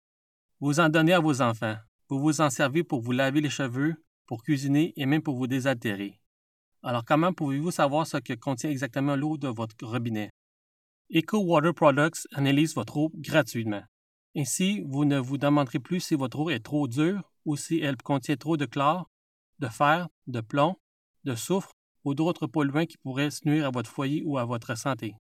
French (Canada) voiceover